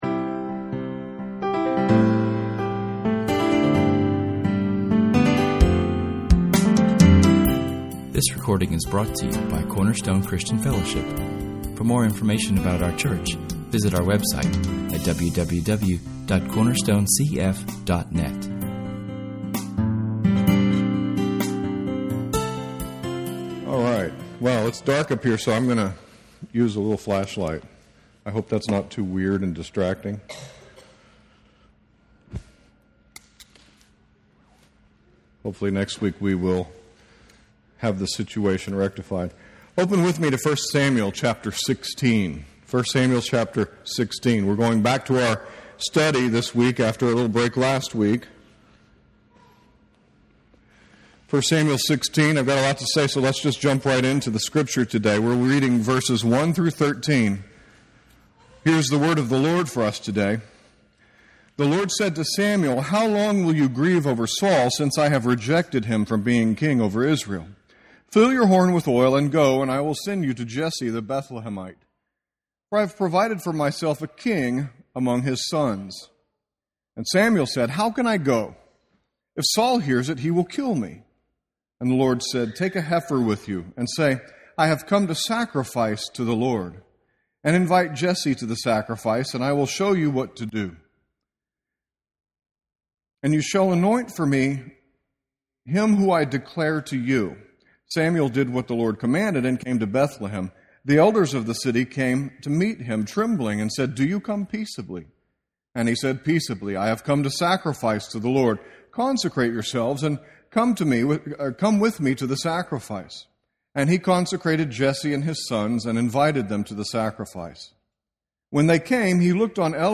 Note: We experienced some audio issues this Sunday, the first minute or two of audio are slightly distorted.